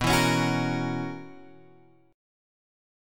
B Major 7th Suspended 2nd Suspended 4th